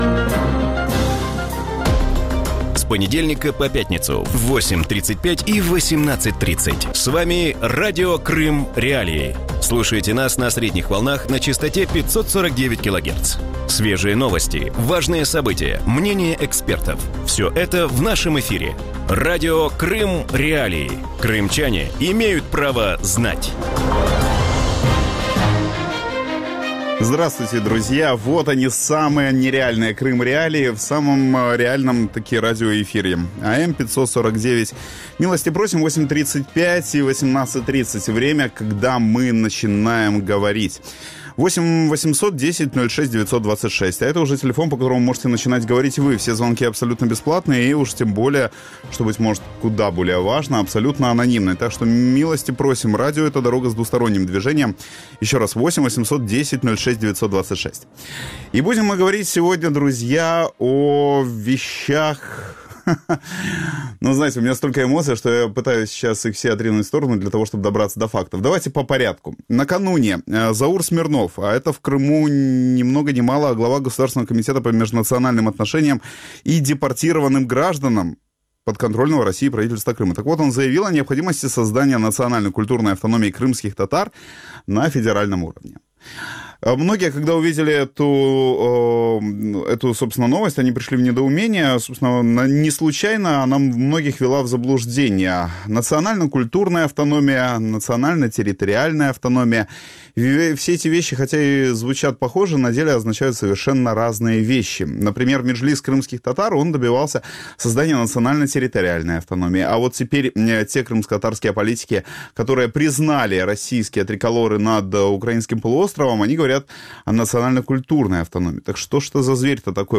В эфире Радио Крым.Реалии обсуждали, что хорошего или плохого может получиться из национально-культурной автономии крымских татар